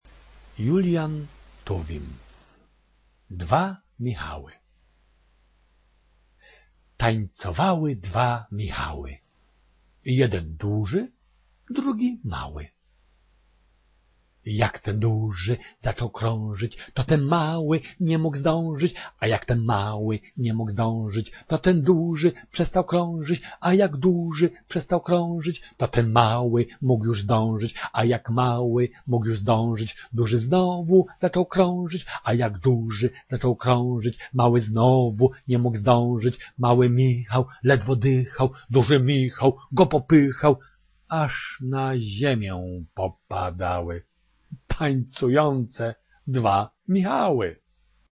Послушайте и скажите, какие такие танцы напоминает вам его исполнение:
Лично мне (под впечатлением от недавних крымских событий ощутившему, вероятно, мистическую связь времён и народов) представляется такая экзотическая картина: ханский дворец где-нибудь в Бахчисарае, нещадно палящее крымское солнце, толпа праздных зевак, бубен-бубен-бубен-бубен и — невесть откуда взявшиеся тут заезжие дервиши, в бешеном кручении-верчении доводящие себя до экстаза, чтобы в один миг рухнуть потом в полном изнеможении на пыльную землю…